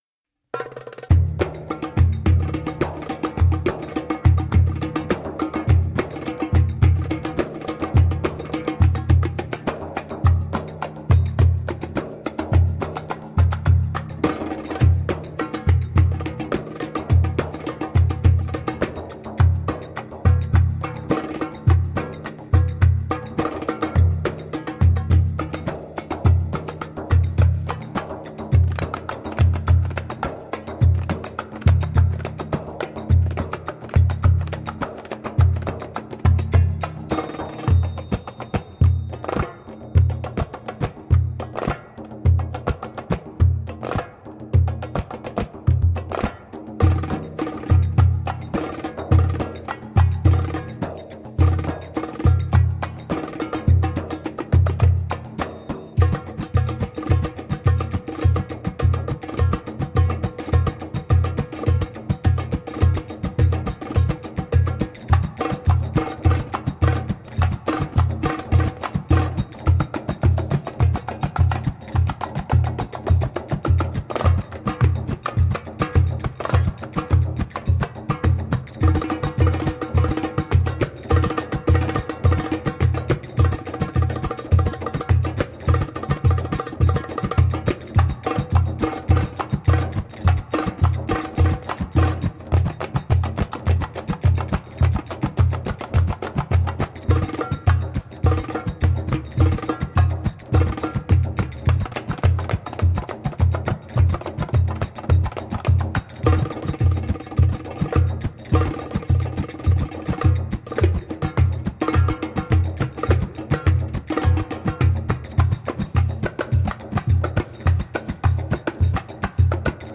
Passionate eastern percussion.
Tagged as: World, Other, Arabic influenced